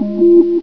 bloop.ogg